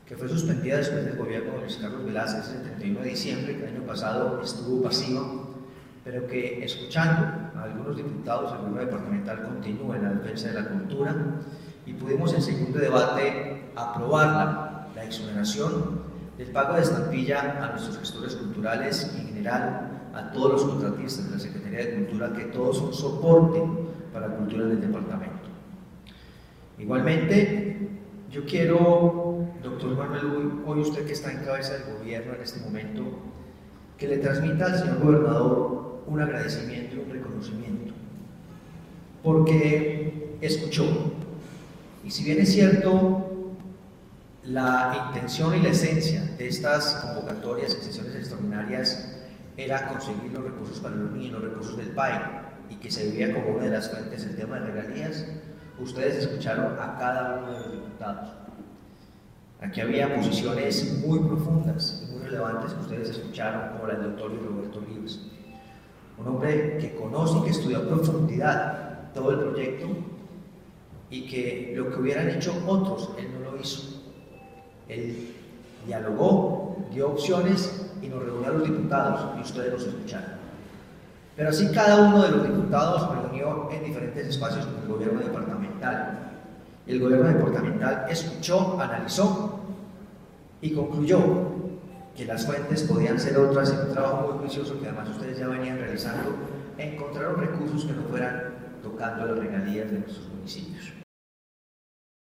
Presidente de la Asamblea, Hernán Alberto Bedoya.
Hernan-Alberto-Bedoya-presidente-Asamblea-de-Caldas-1.mp3